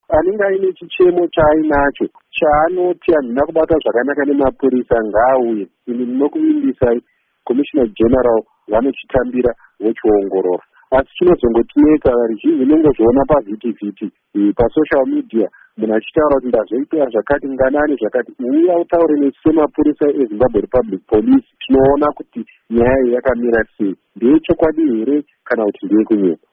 Mashoko aCommissioner Nyathi